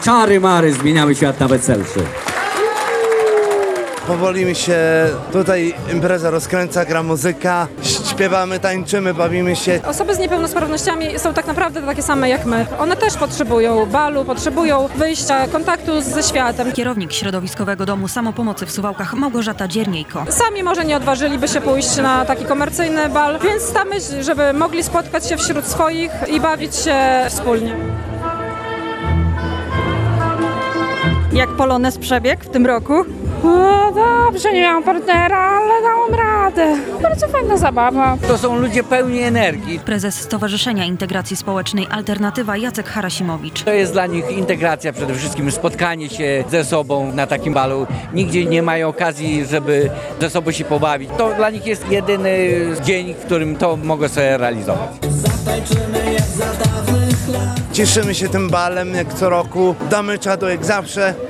Regionalny Bal Andrzejowy Osób z Niepełnosprawnościami - relacja